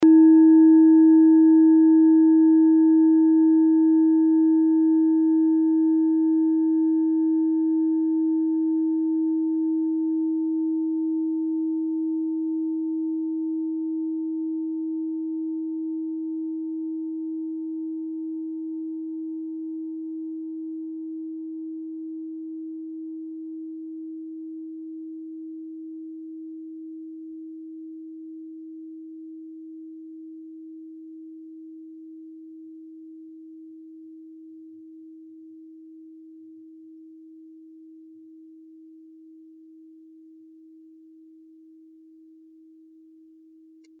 Tibet Klangschale Nr.1
Sie ist neu und wurde gezielt nach altem 7-Metalle-Rezept in Handarbeit gezogen und gehämmert.
Hörprobe der Klangschale
(Ermittelt mit dem Filzklöppel oder Gummikernschlegel)
Diese Frequenz kann bei 160Hz hörbar gemacht werden; das ist in unserer Tonleiter nahe beim "E".
klangschale-tibet-1.mp3